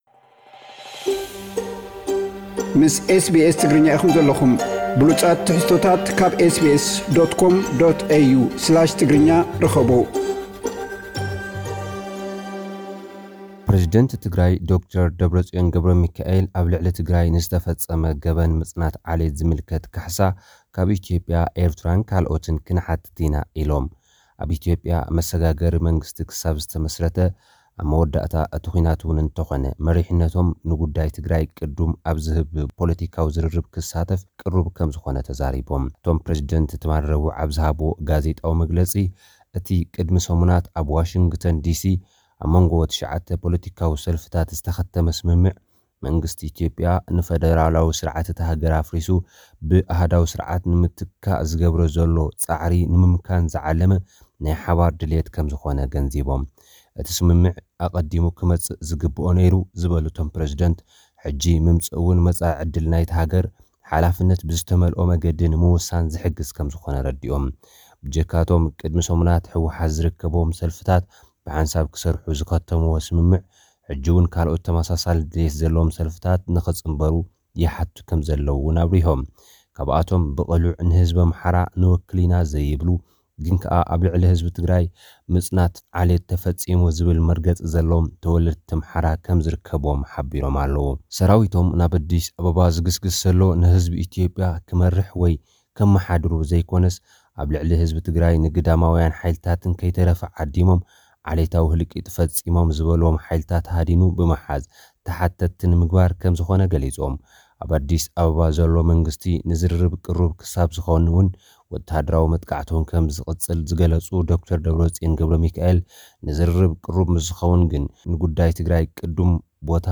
ሓጸርቲ ጸብጻባት፥
ዝብሉ ሓጸርቲ ጸብጻባት ልኡኽና ክቐርብዩ።